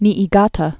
(nēē-gätə, -tä)